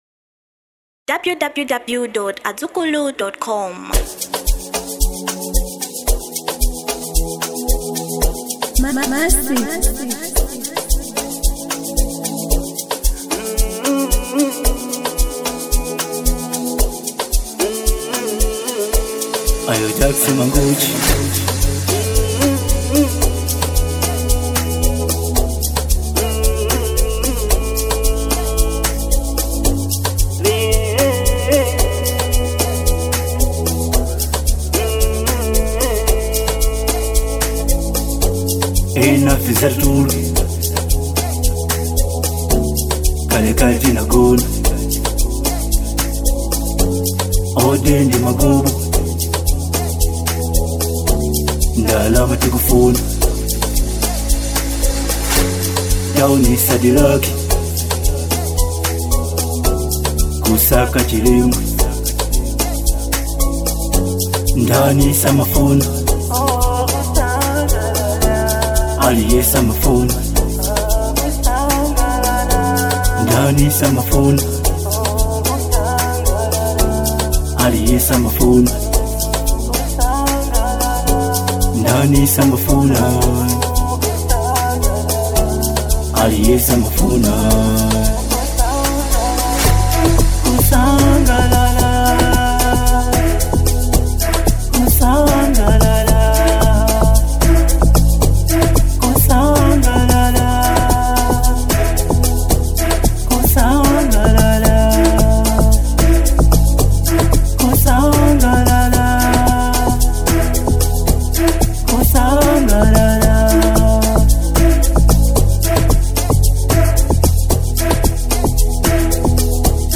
Genre Amapiano